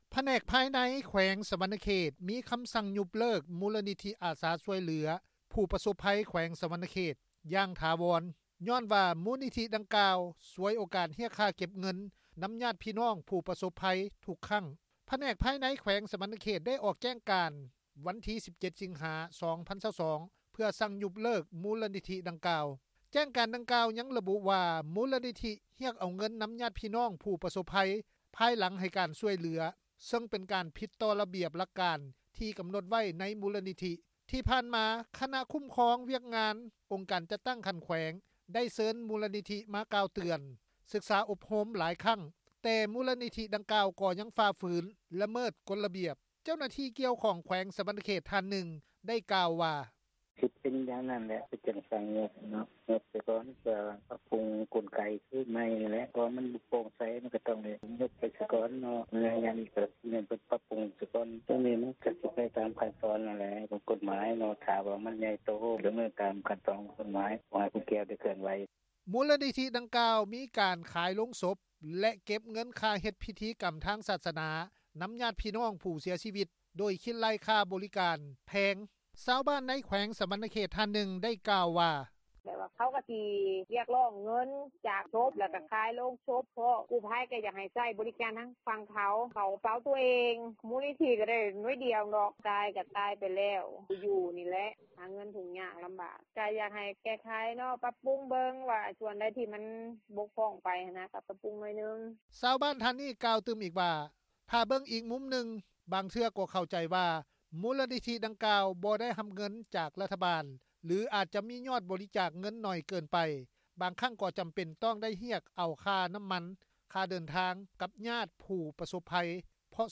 ເຈົ້າໜ້າທີ່ກ່ຽວຂ້ອງ ແຂວງສວັນນະເຂດ ທ່ານນຶ່ງ  ໄດ້ກ່າວວ່າ:
ຊາວບ້ານ ໃນແຂວງສວັນນະເຂດ ທ່ານນຶ່ງ ໄດ້ກ່າວວ່າ:
ເຈົ້າໜ້າທີ່ກູ້ພັຍ ທ່ານນຶ່ງ ຢູ່ນະຄອນຫລວງວຽງຈັນ ກ່າວໃນວັນທີ 25 ສິງຫາ ວ່າ: